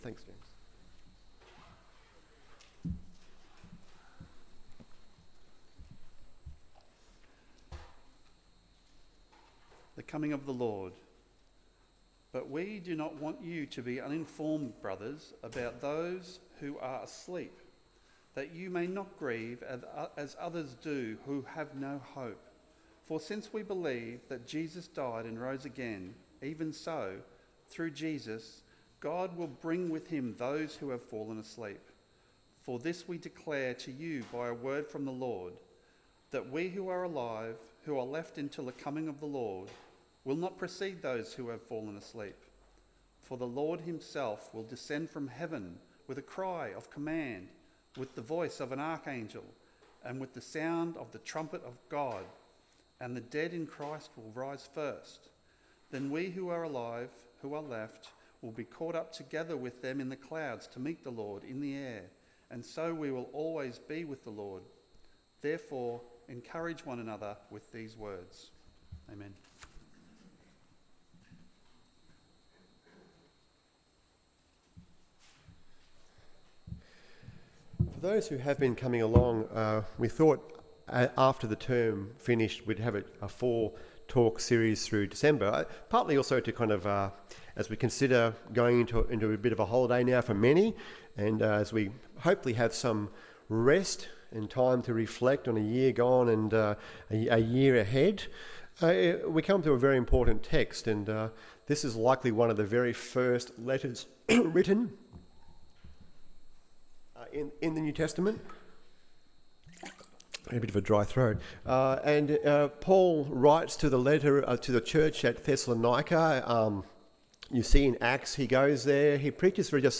Will Christians Who Have Died Miss The Second Coming? AM Service